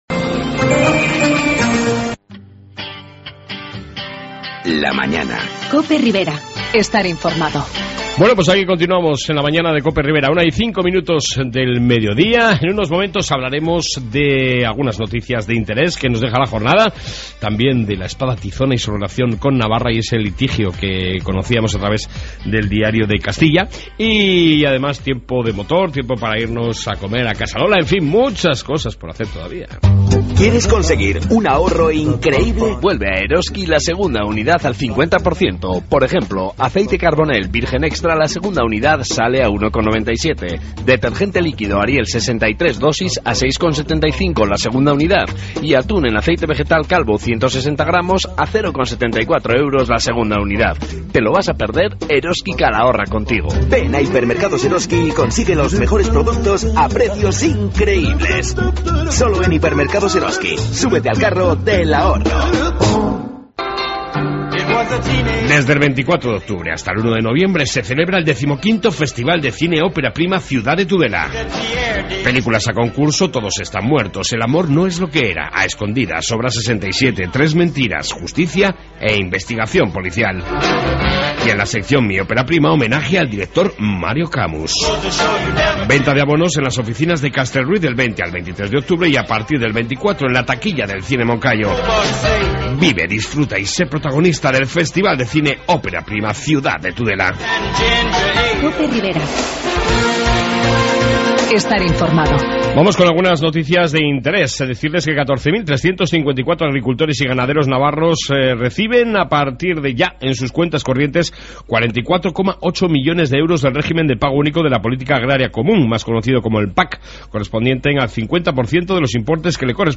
AUDIO: En esta 2 parte Informativo, entrevista sobre la Tizona del Cid y navarra y tiempo de motor